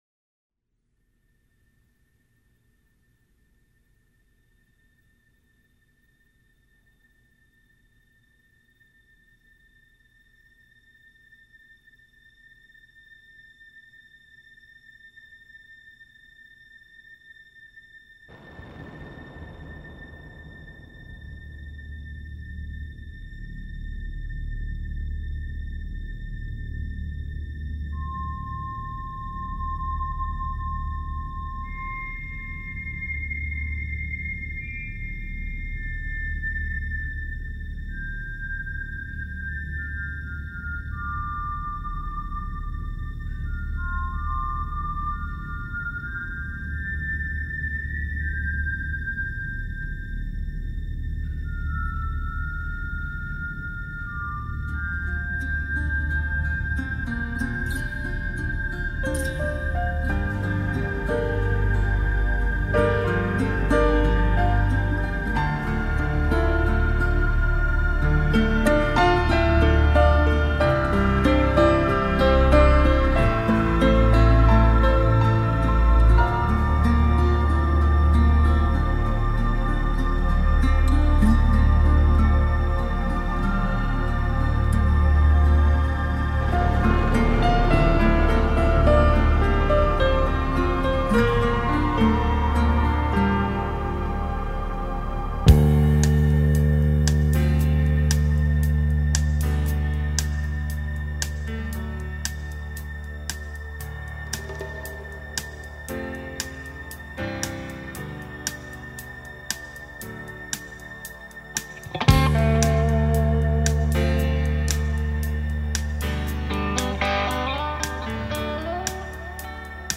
کلاسیک راک